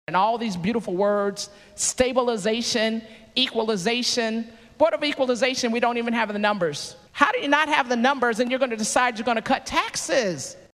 CLICK HERE to listen to commentary from Regina Goodwin.